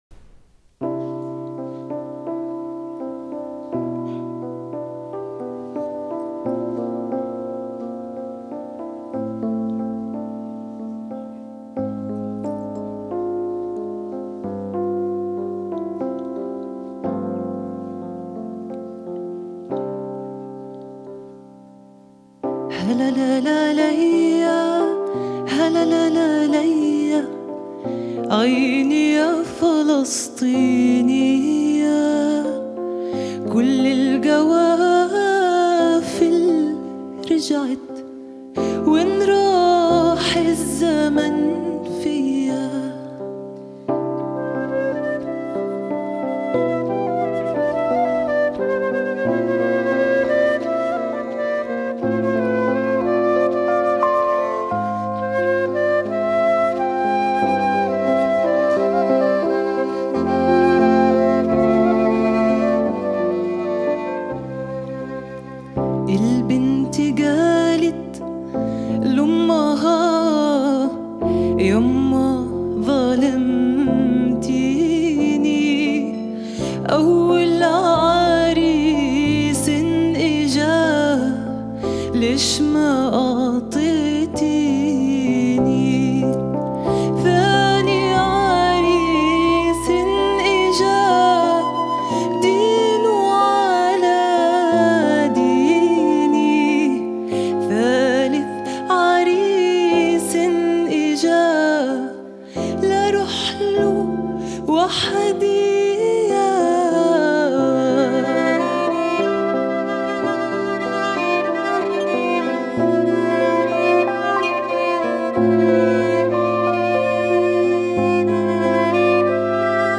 traditional Palestinian song
Recorded at REMIX music workshop – Jesuite Theatre – Alexandria 2006